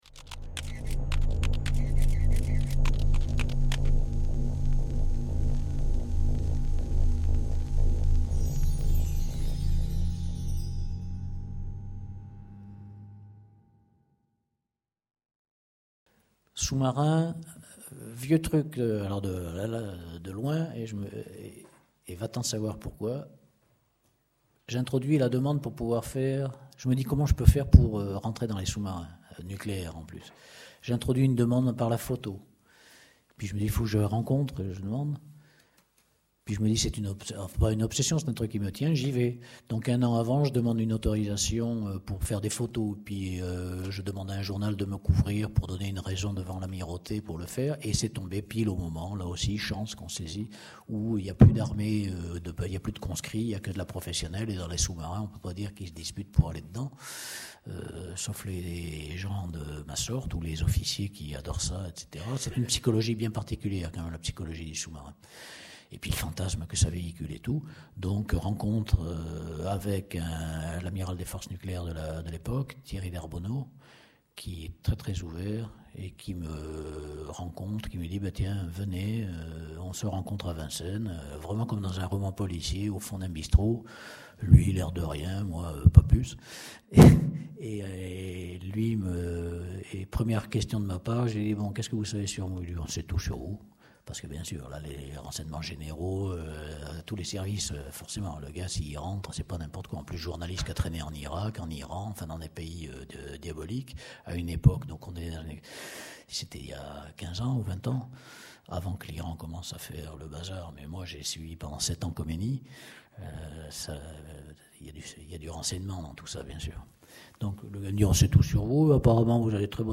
Master classe Jean Gaumy, enregistré le 23 novembre 2013 à l'EHESS.
Rencontre avec Jean Gaumy, cinéaste et photographe de l’agence Magnum, animée par le critique et cinéaste Alain Bergala.